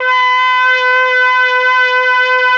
RED.BRASS 29.wav